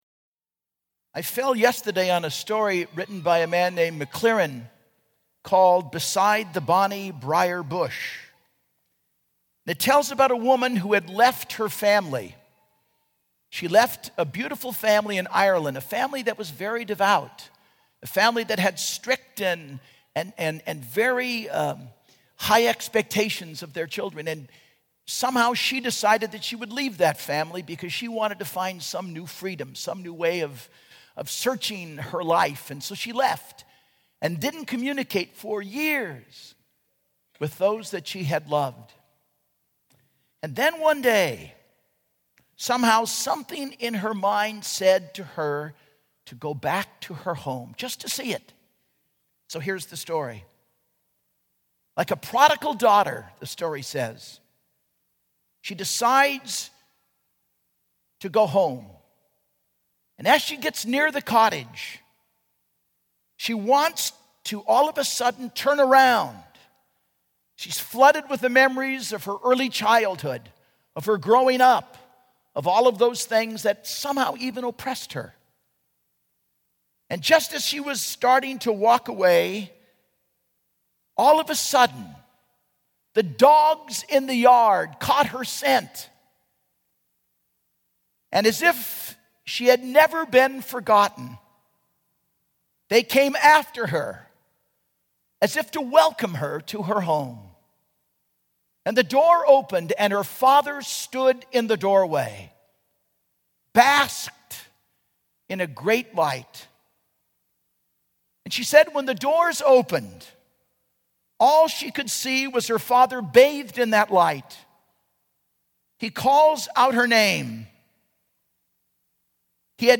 Homily - 12/24/10 - Christmas Eve
Homily_ChristmasEve.mp3